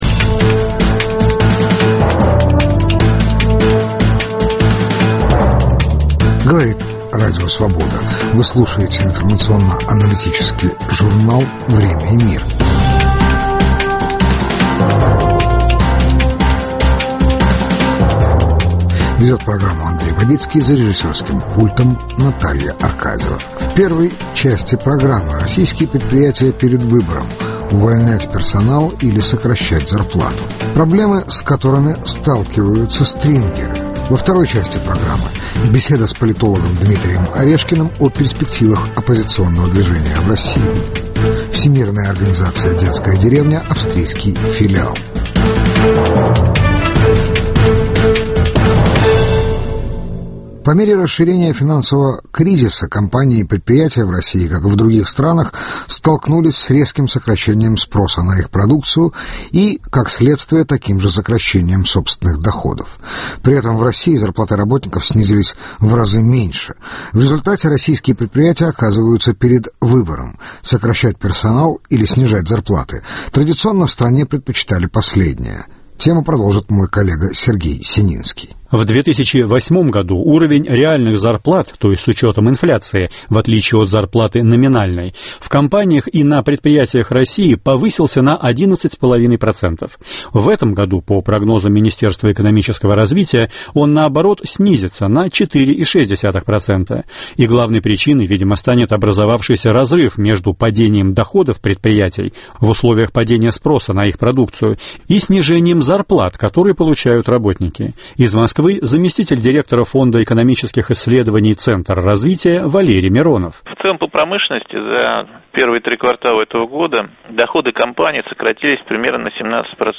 Российские предприятия перед выбором: увольнять персонал или сокращать зарплату. Проблемы, с которыми сталкиваются внештатные корреспонденты. Беседа с политологом Дмитрием Орешкиным о перпективах оппозиционного движения в России.